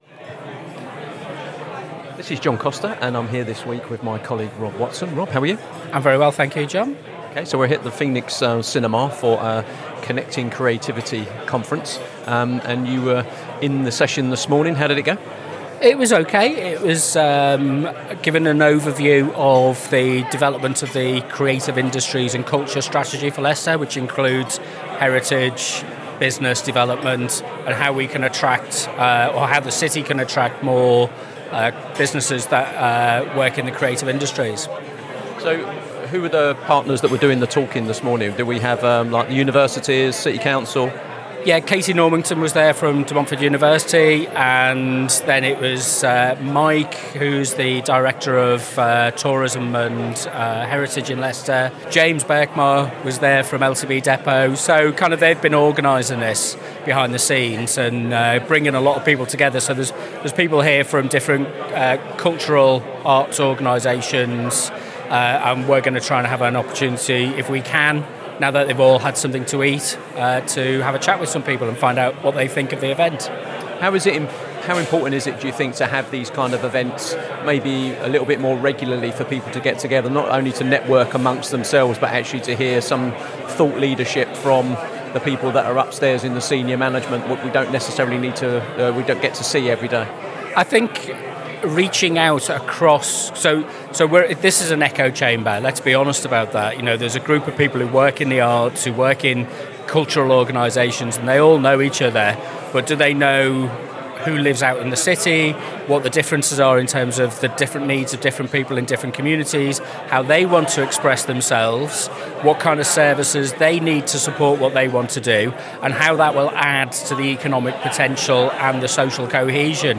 This week on Spotlight on Arts on Soar Sound, we bring you a special edition recorded at the Leading Leicester: Connecting Creativity Conference, hosted at the Phoenix Cinema and Arts Centre.
From heritage and fashion to digital art and civic engagement, this episode captures a live moment of civic and cultural reflection.